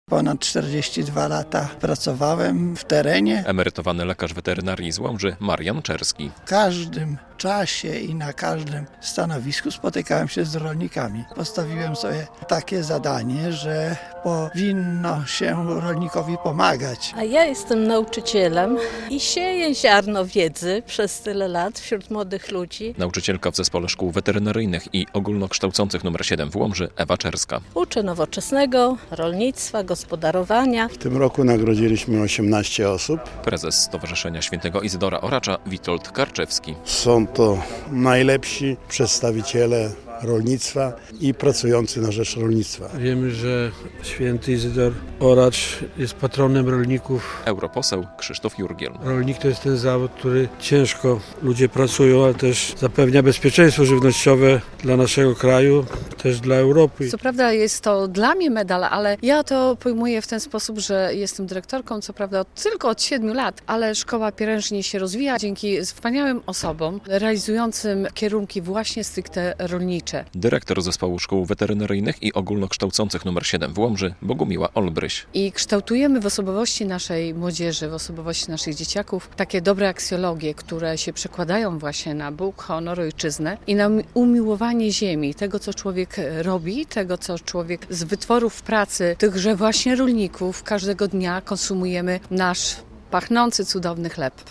Uroczystość zorganizowano w kościele pw. Ducha Świętego.